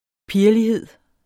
Udtale [ ˈpiɐ̯ʌliˌheðˀ ]